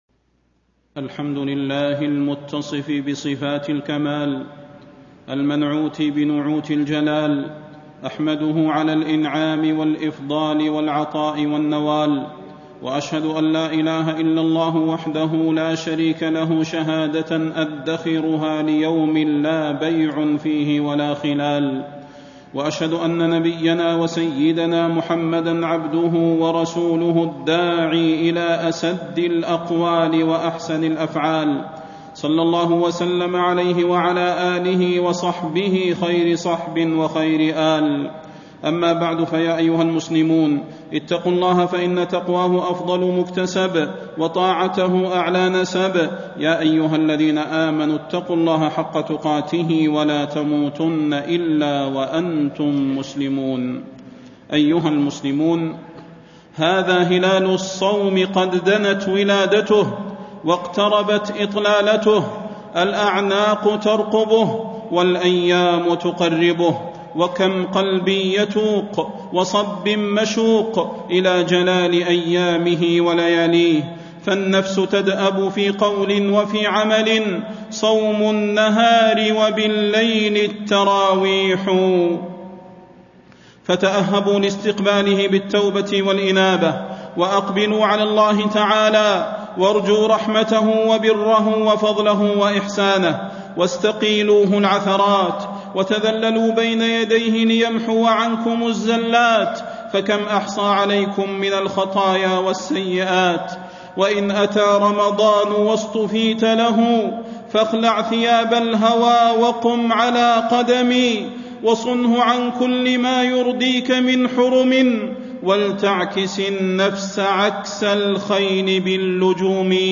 تاريخ النشر ٢٢ شعبان ١٤٣٥ هـ المكان: المسجد النبوي الشيخ: فضيلة الشيخ د. صلاح بن محمد البدير فضيلة الشيخ د. صلاح بن محمد البدير استقبال الشهر المبارك